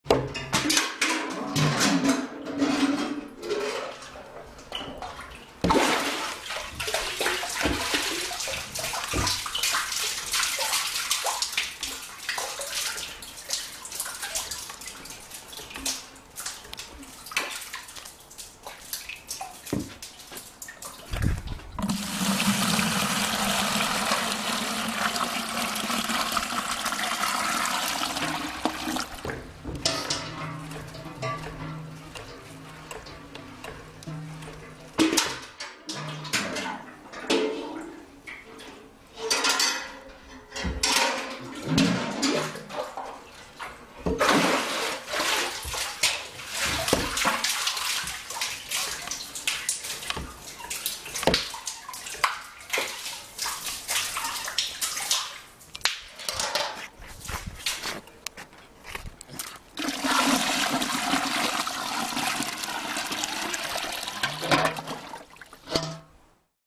Звуки колодца
Звук набора чистой воды из родника в колодце